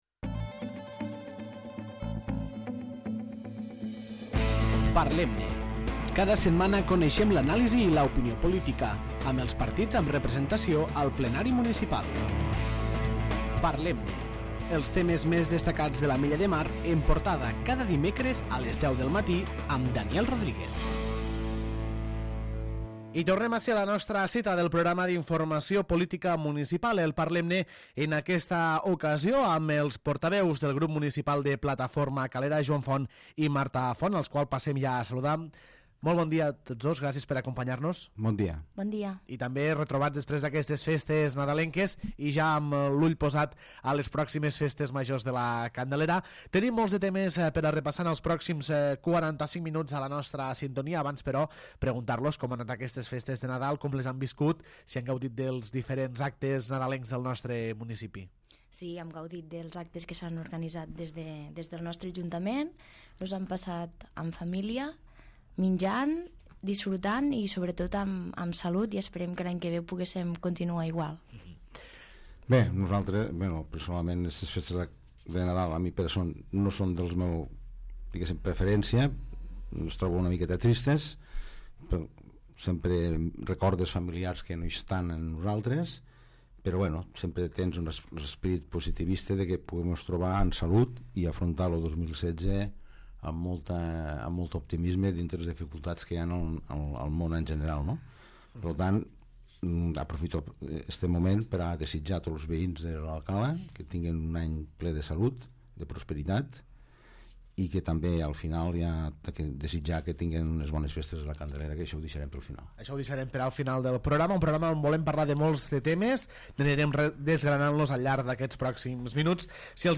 Nova edició del programa, amb la participació de Joan Font i Marta Font, regidors del grup municipal de Plataforma Calera.